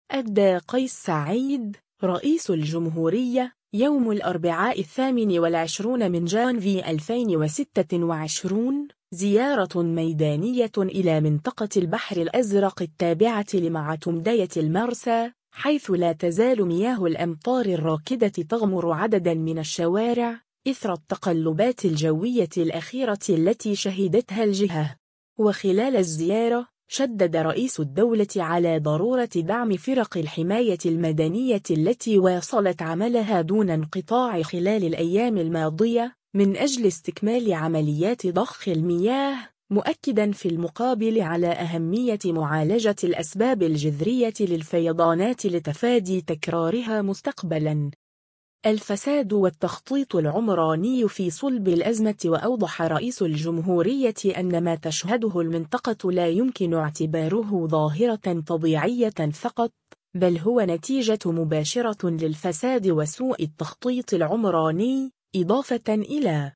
أدّى قيس سعيّد، رئيس الجمهورية، يوم الأربعاء 28 جانفي 2026، زيارة ميدانية إلى منطقة البحر الأزرق التابعة لمعتمدية المرسى، حيث لا تزال مياه الأمطار الراكدة تغمر عدداً من الشوارع، إثر التقلبات الجوية الأخيرة التي شهدتها الجهة.
وخلال جولته، تحادث رئيس الجمهورية مع عدد من سكان البحر الأزرق، مستمعاً إلى مشاغلهم وملاحظاتهم، ومثمّناً روح التضامن والتآزر التي أظهروها في مواجهة الأضرار التي خلّفتها الأمطار.